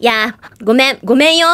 Worms speechbanks
Traitor.wav